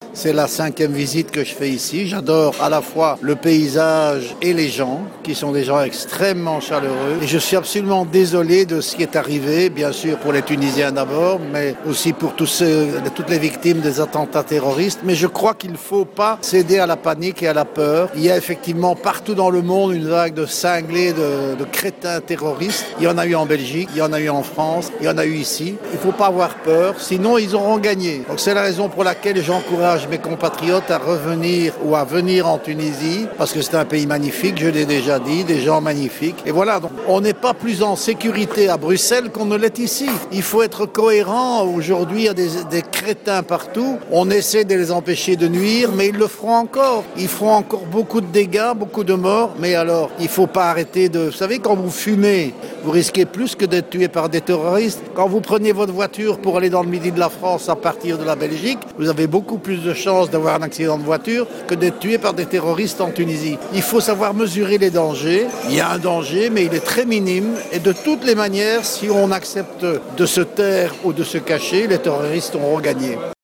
Dans une déclaration accordée à Jawhara FM, Josy Dubié, un sénateur belge, a tenu à exprimer son amour pour la Tunisie et les raisons de son soutien au tourisme tunisien et en particulier celui de la ville de Sousse.